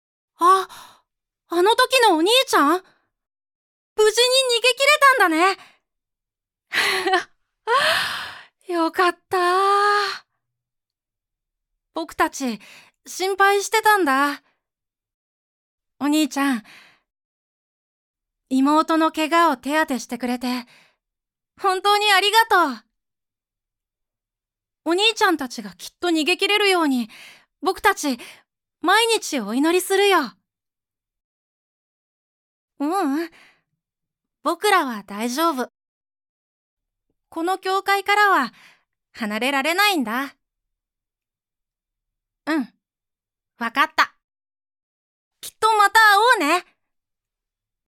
男の子 – グリーンノート｜世界を彩る声で、共に。